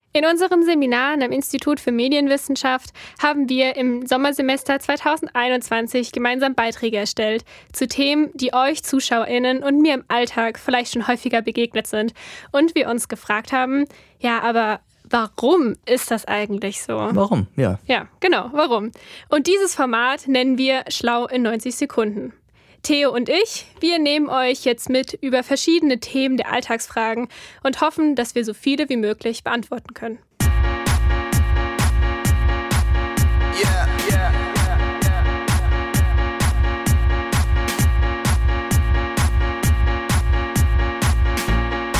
Micro-Europa_Sendung_401_Teaser.mp3